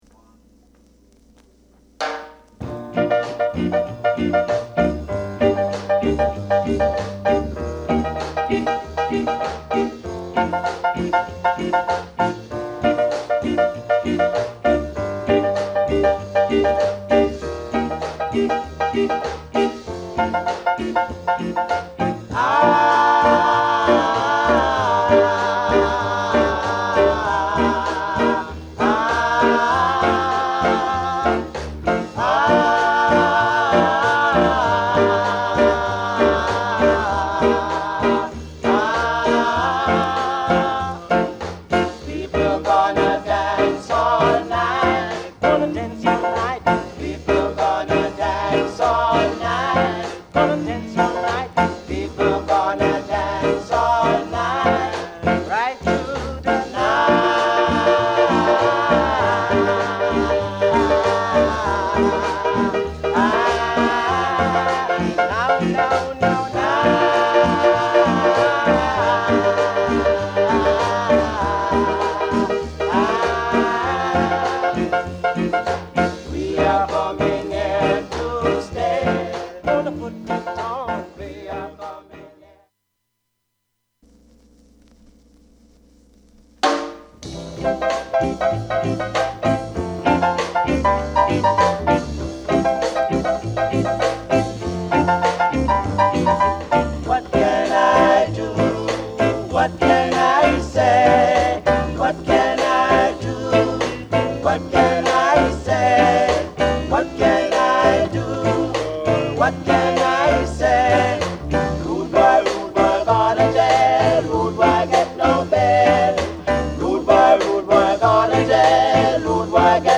Genre: Rocksteady
Federal Record Studioでの録音で